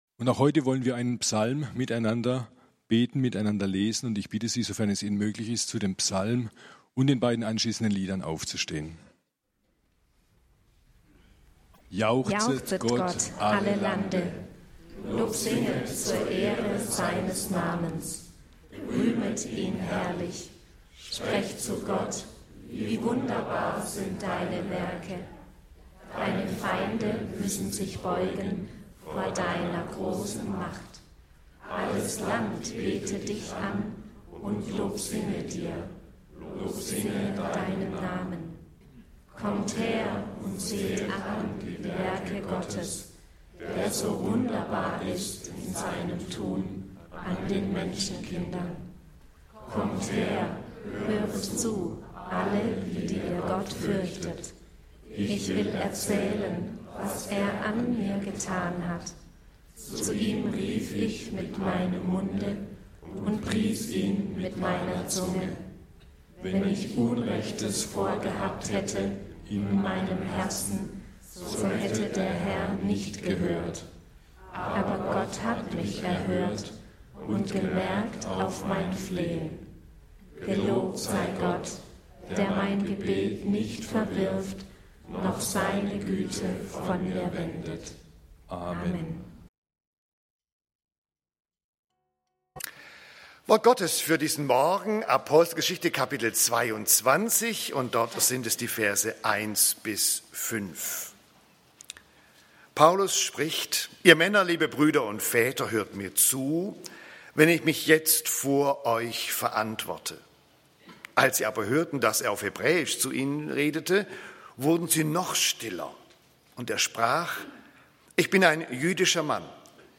Paulus und seine Mitarbeiter (Apg. 22, 1-5) - Gottesdienst